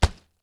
THUD_Medium_01_mono.wav